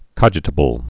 (kŏjĭ-tə-bəl)